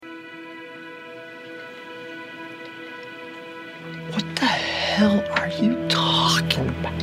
Talking Sound Effects MP3 Download Free - Quick Sounds